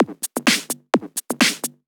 Electrohouse Loop 128 BPM (2).wav